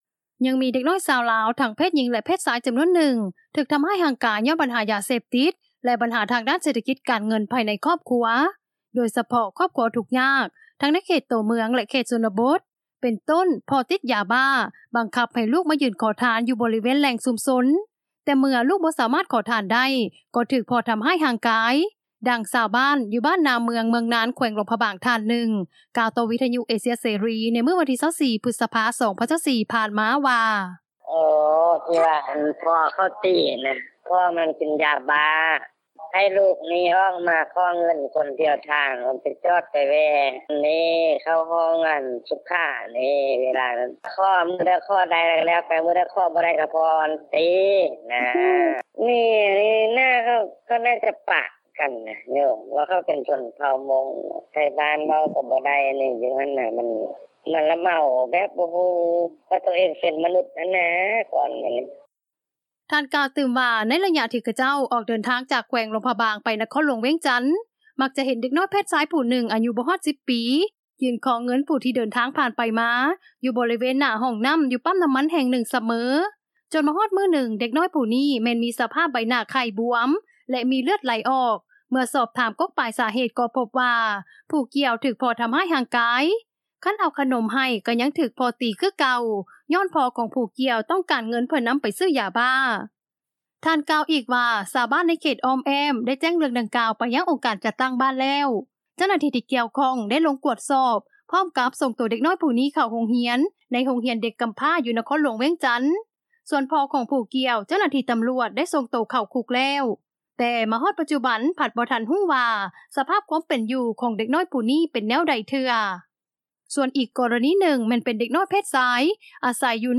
ນັກຂ່າວພົນລະເມືອງ